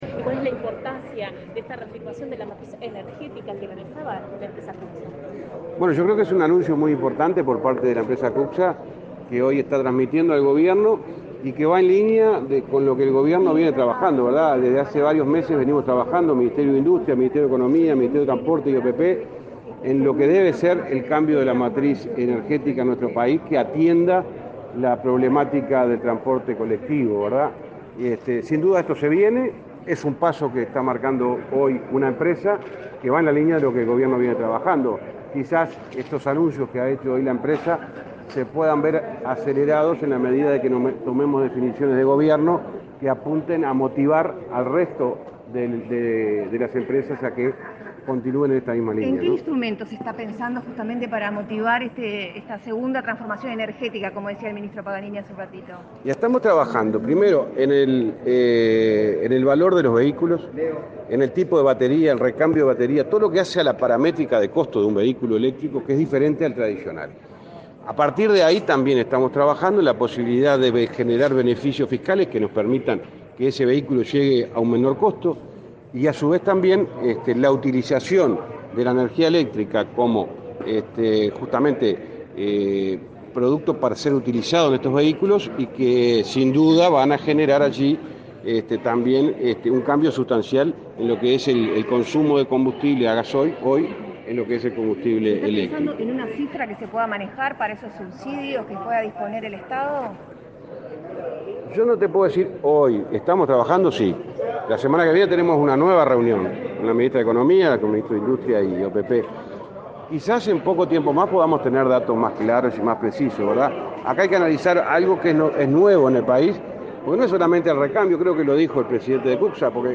Declaraciones de prensa del ministro de Transporte y Obras Públicas, José Luis Falero
Este 8 de marzo se realizó el lanzamiento del cambio de la matriz energética y monitoreo del nivel del CO2 en el transporte público por parte de la empresa Cutcsa, con la presencia del presidente de la República, Luis Lacalle Pou. Tras el evento, el ministro de Transporte, José Luis Falero, efectuó declaraciones a la prensa.